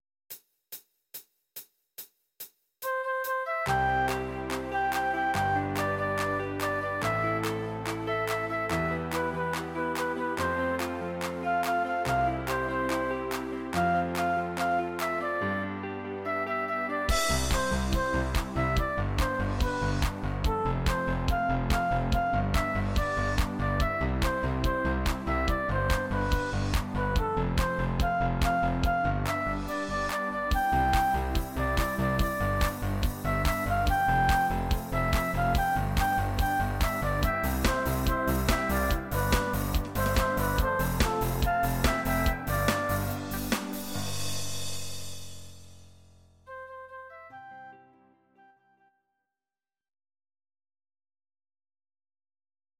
Audio Recordings based on Midi-files
Our Suggestions, Pop, German, 2020s